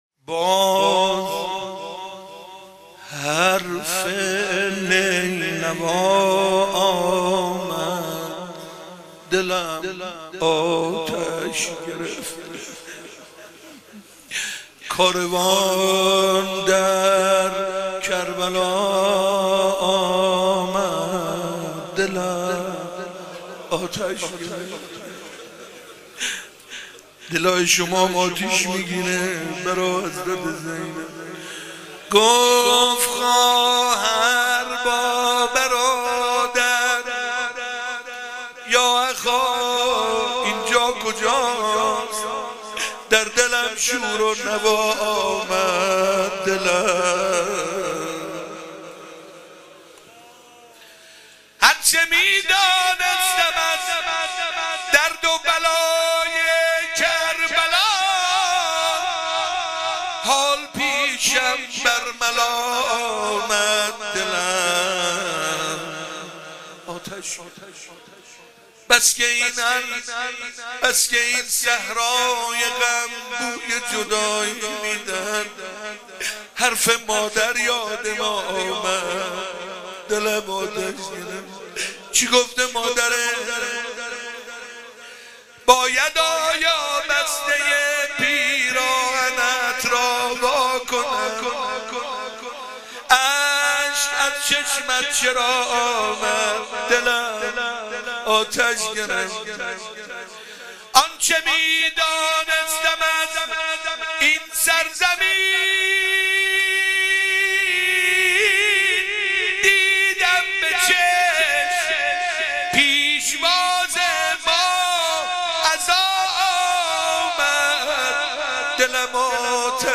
نوحه شب دوم
روضه ورودیه شب دوم